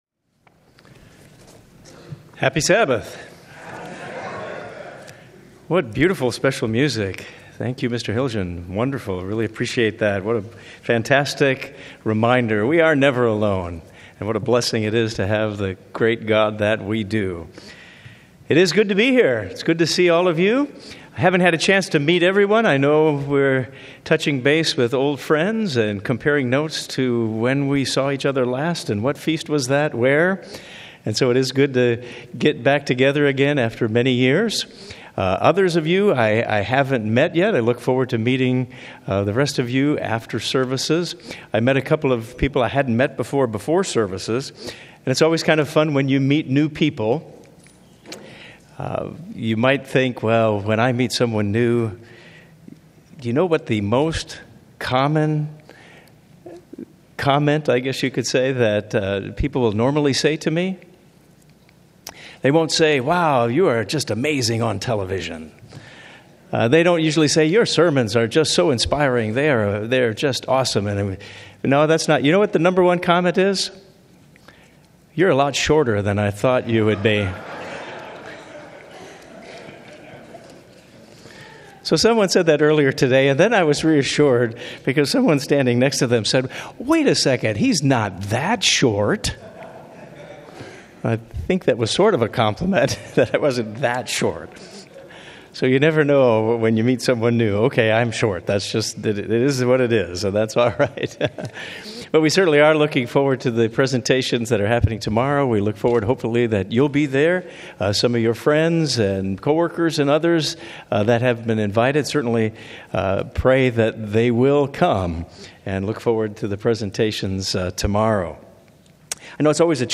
But, what should be our focus? This sermon addresses our approach and focus concerning Passover.